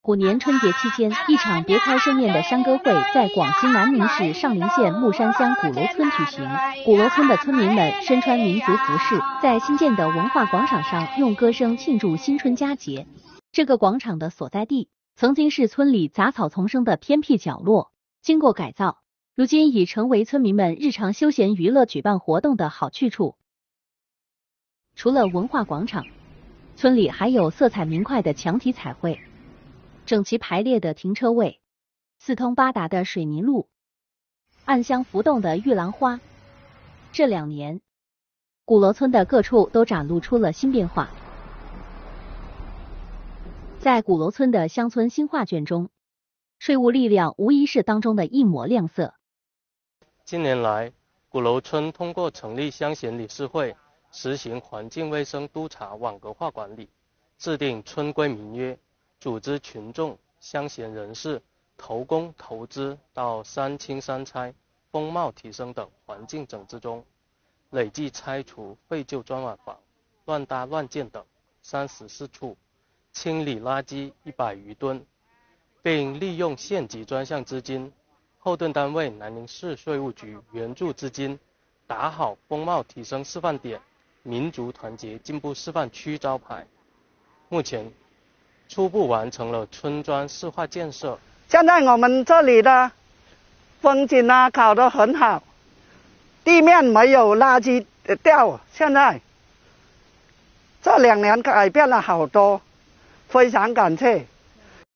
虎年春节期间，一场别具风情的山歌会在广西南宁市上林县木山乡古楼村举行。古楼村的村民们身穿民族服饰，在新建的文化广场上用歌声庆祝新春佳节的来临。
古楼村开展2022年新春山歌会。